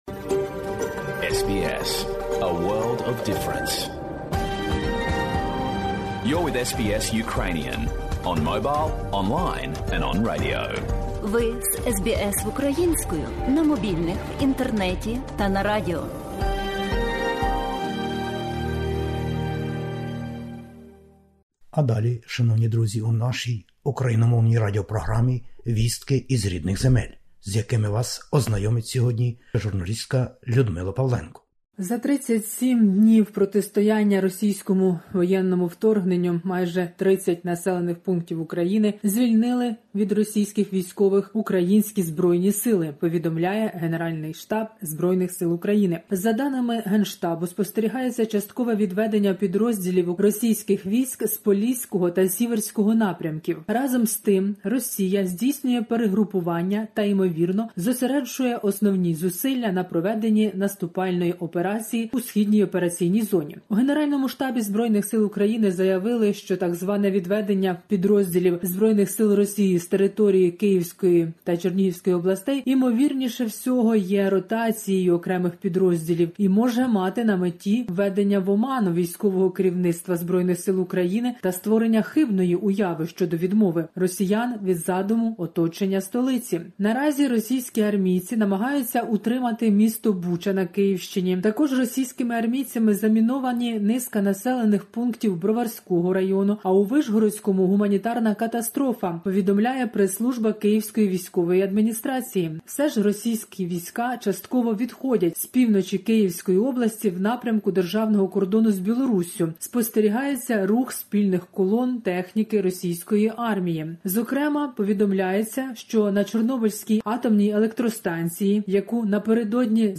Добірка новин із України. Війна: звільнено українськими військами майже 30 сіл і міст. Українців вивезено до Росії та Білорусі.